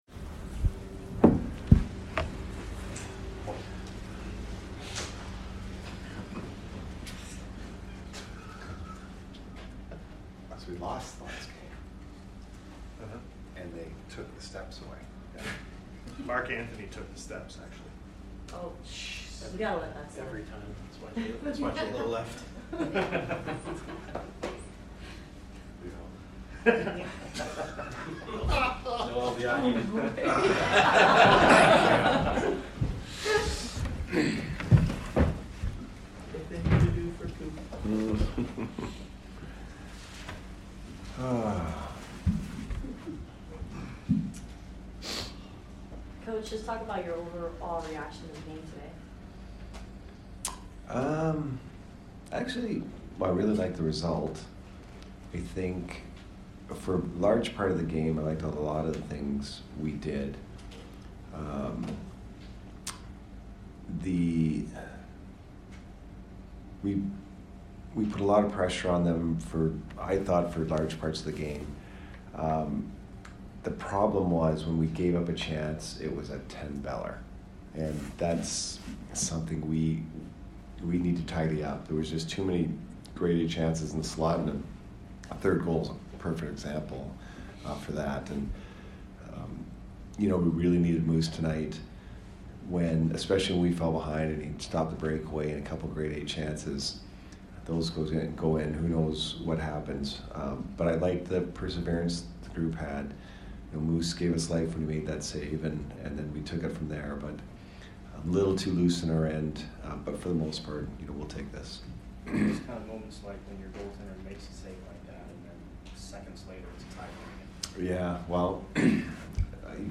Head Coach Jon Cooper Post Game 11/5/22 vs BUF